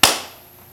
tlesknuti.wav